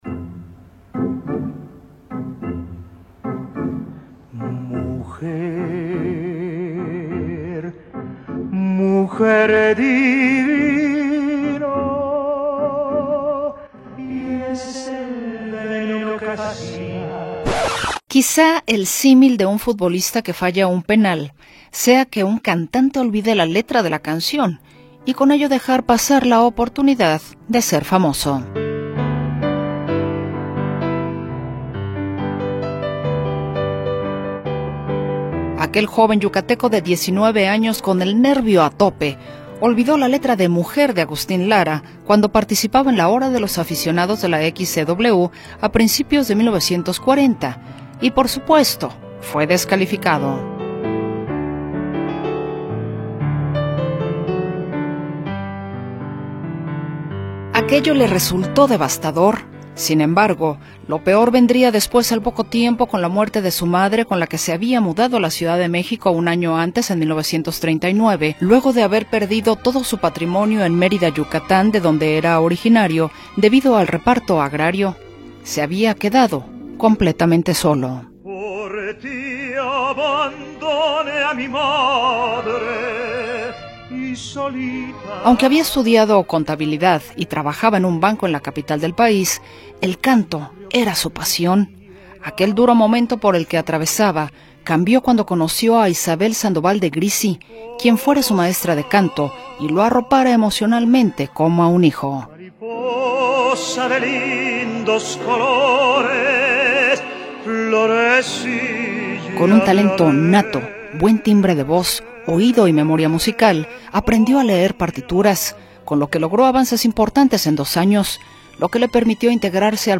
cantante operístico mexicano